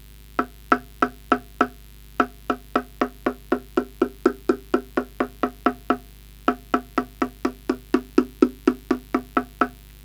Knackning mitt på ringmoden vid U ger ”referenston.  Därefer knackar jag från U utåt mot UL och den övre klossen till vänster och sedan tillbaka till U. Jag går sedan tillbaka till U och knackar startreferens och sedan ut mot mot UR och den övre klossen till höger och tillbaka till U.
Man kan tydligt höra att nodlinjerna inte är i balans d.v.s. att tonen på mittlinjen inte är densamma som då man går ut mot klossarna.
strad_bottom_upper_ring_node.wav